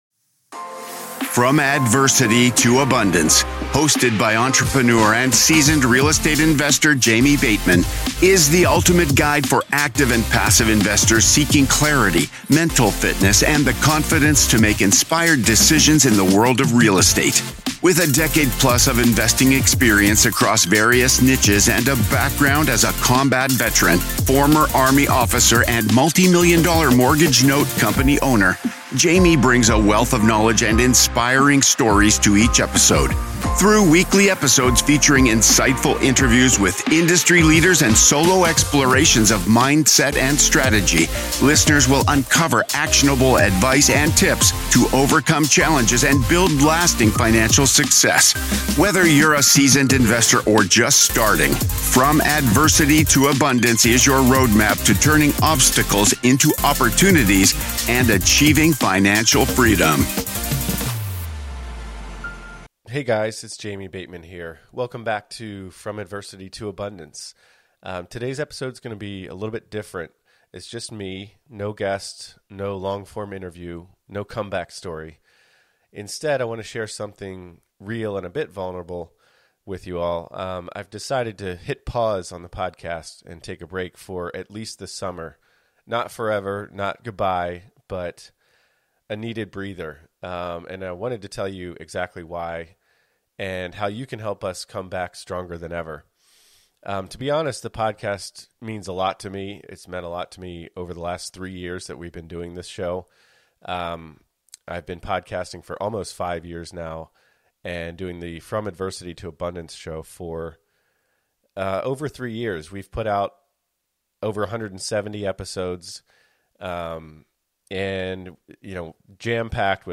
In this heartfelt solo episode